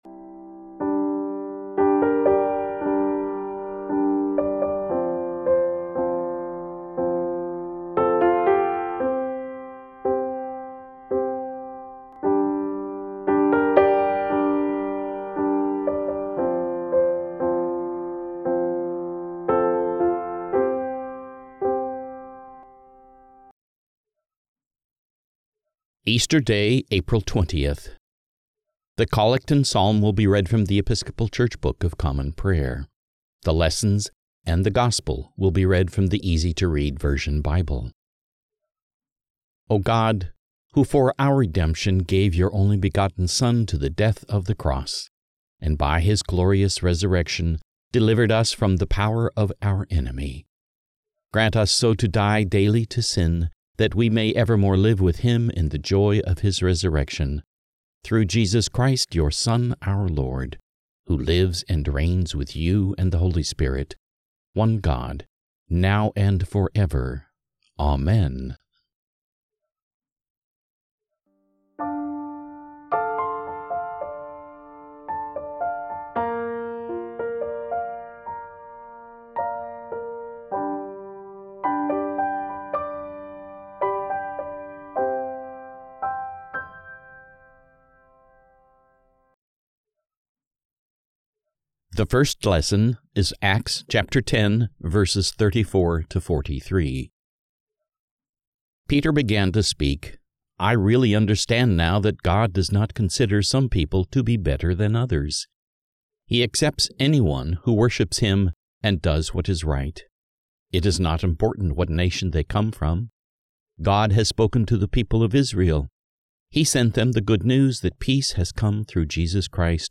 The Collect and Psalm will be read from The Episcopal Church Book of Common Prayer.
The Lessons and the Gospel will be read from The Easy to Read Version Bible